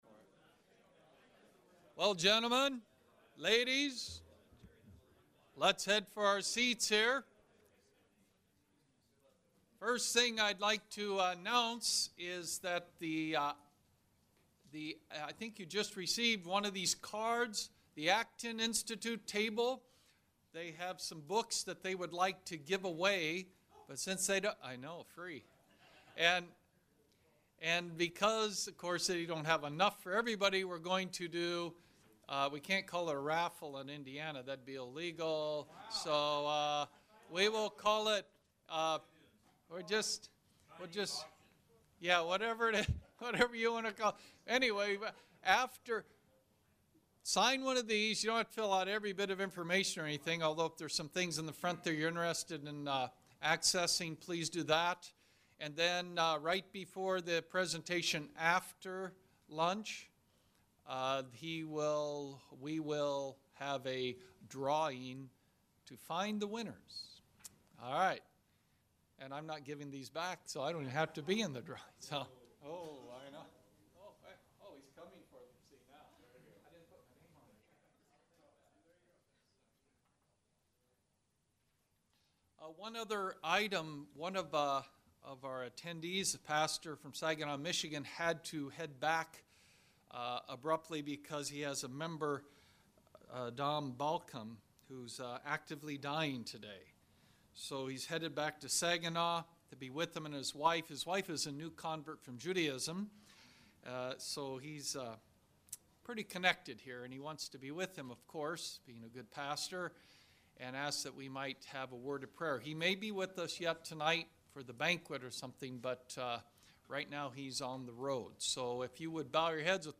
Additional Information: Collections This Item is in 1 Public Collection Urban Ministry Conference: In the World for Good 2013 by CTS This item is in 0 Private Collections Log in to manage and create your own collections.